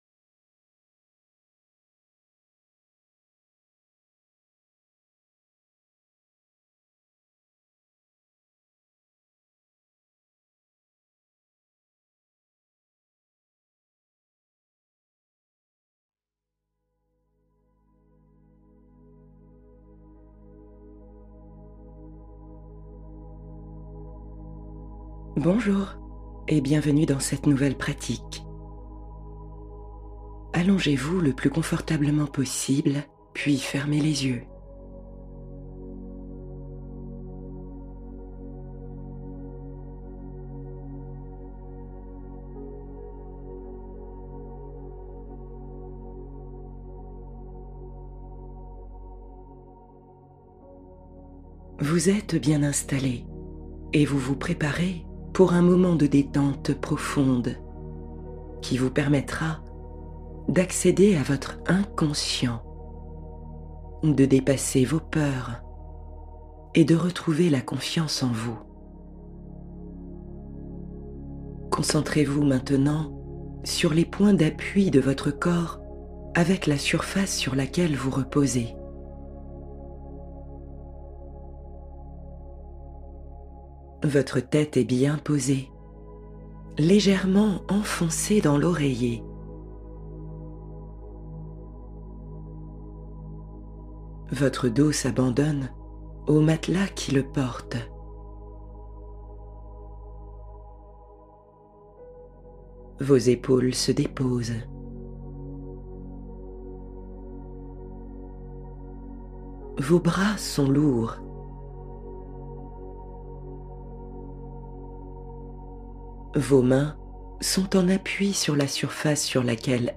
Face à soi-même — Méditation profonde pour renouer avec son essence